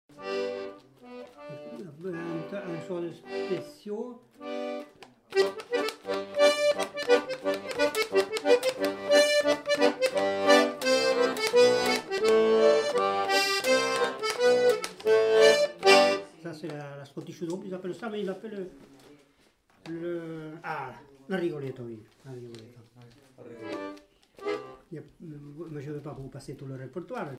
Aire culturelle : Lomagne
Lieu : Pessan
Genre : morceau instrumental
Instrument de musique : accordéon chromatique
Danse : scottish